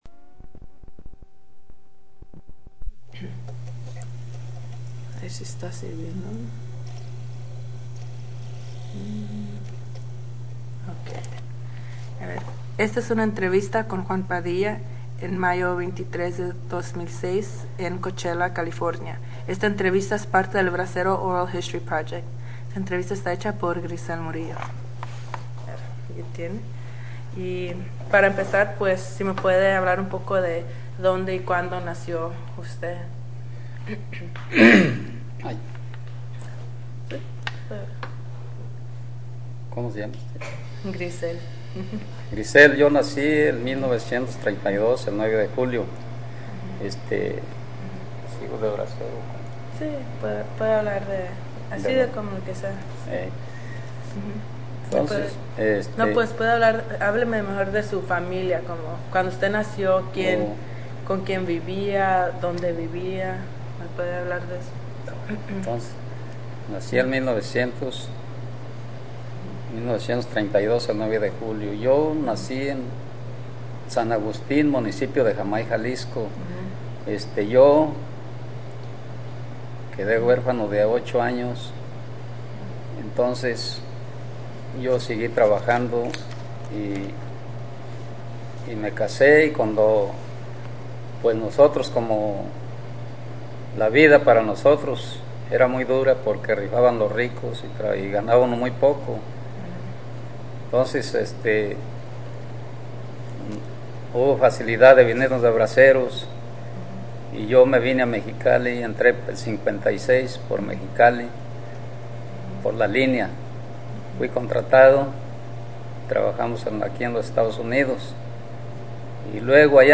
Location Coachella, CA Original Format mini disc